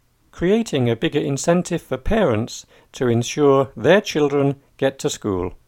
DICTATION 6